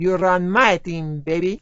gutterball-3/Gutterball 3/Commentators/Maria/maria_goodonebaby.wav at 608509ccbb5e37c140252d40dfd8be281a70f917